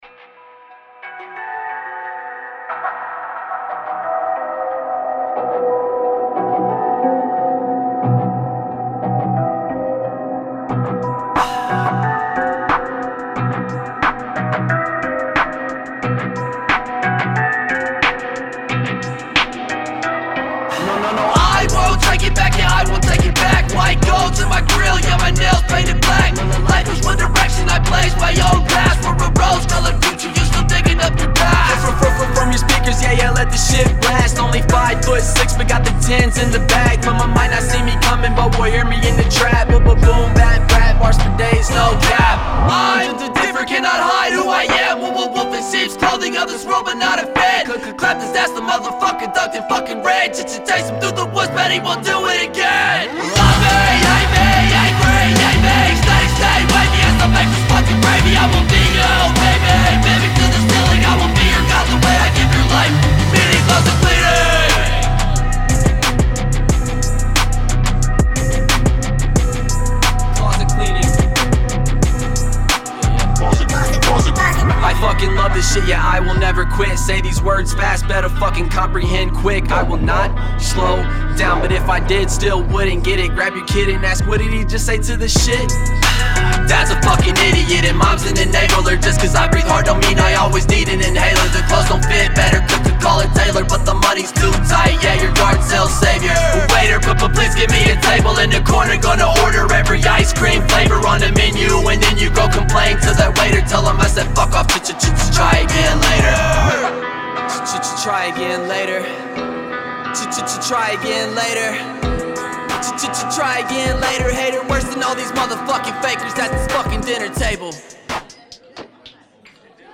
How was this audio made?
We knew we had to get in the studio and get to work.